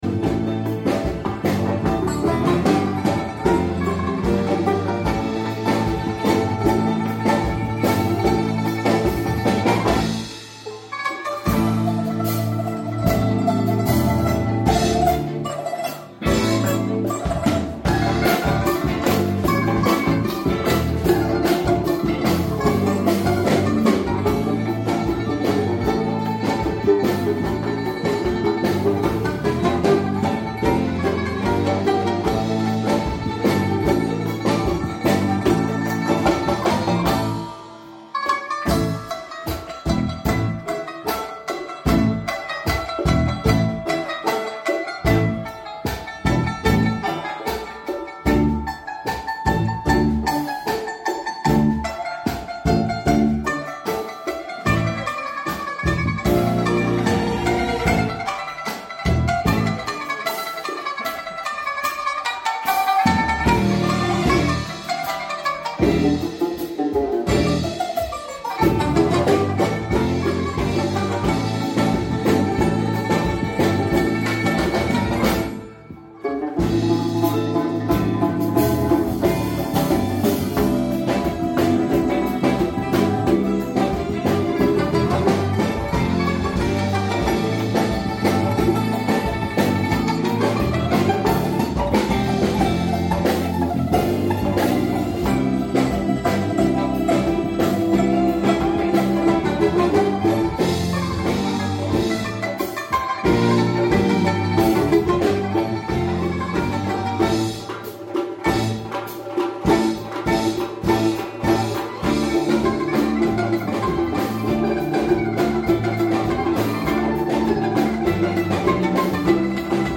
репетиция 2 часть гр.Дервиши Sound Effects Free Download